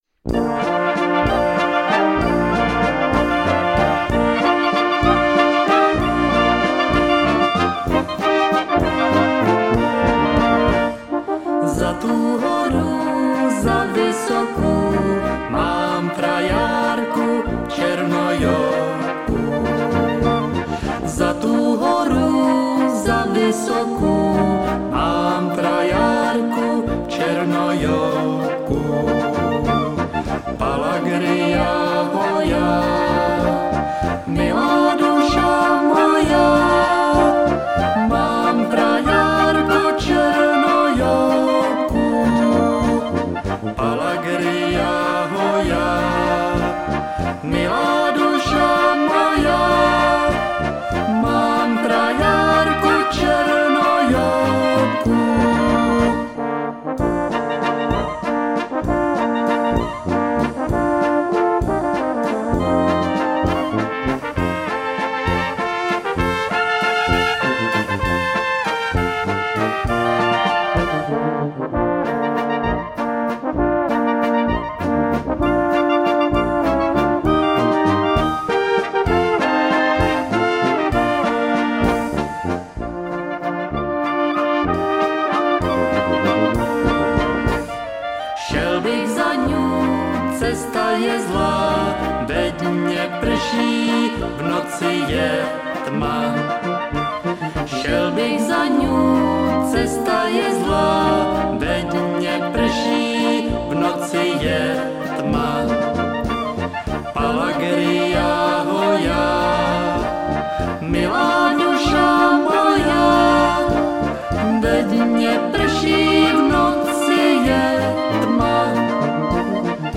Žánr: World music/Ethno/Folk